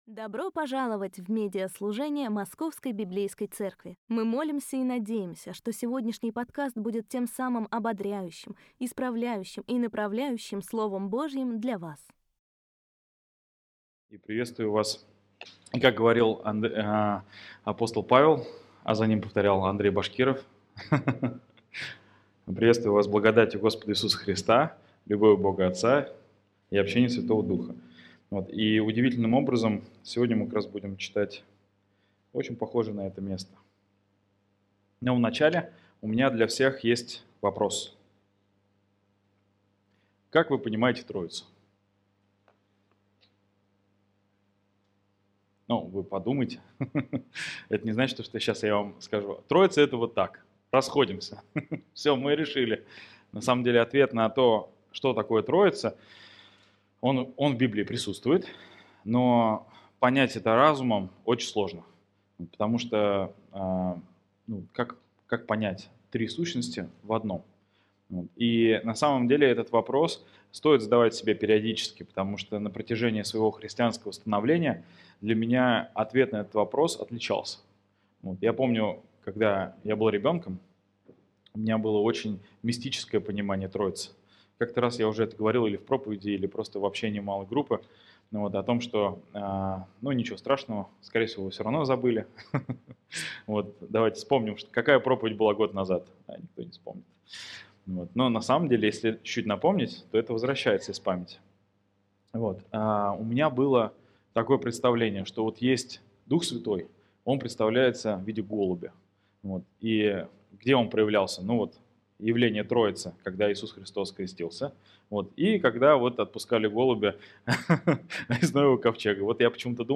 Trinity+Sunday+SERMON.mp3